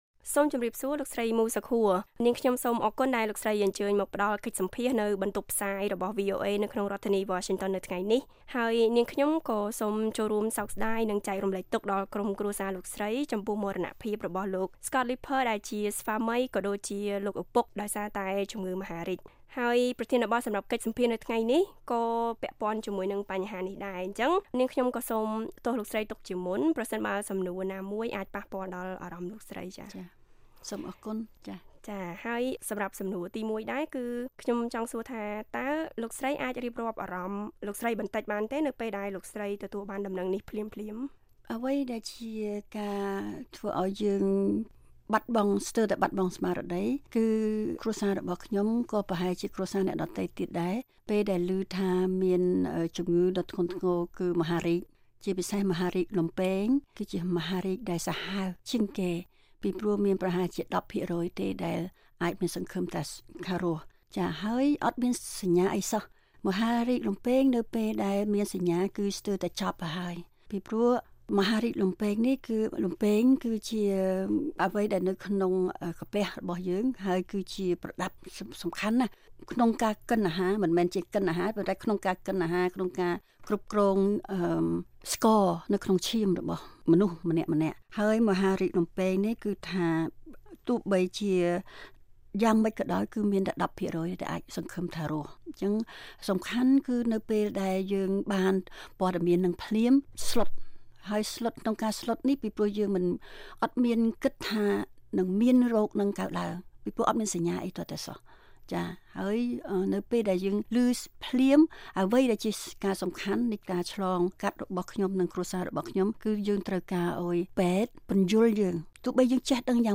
បទសម្ភាសន៍ VOA